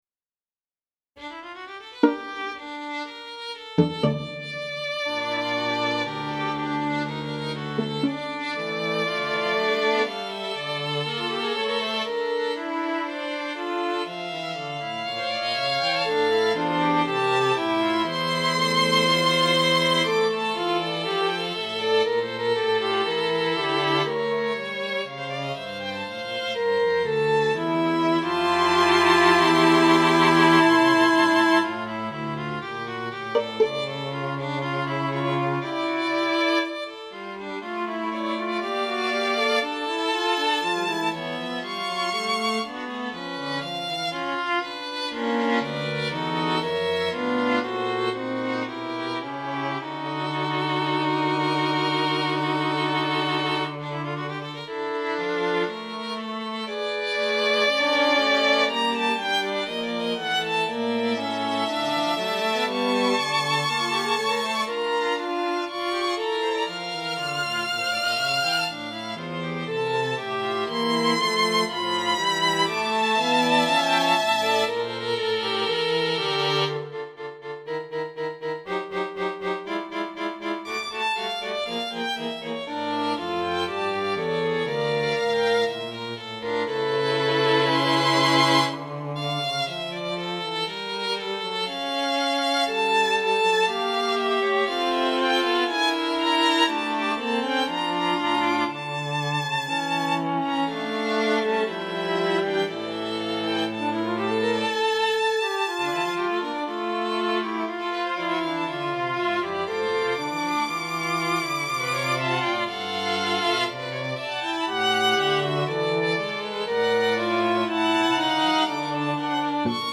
String Quartet No 07
String-Quartet-No-7-1st-Movement.mp3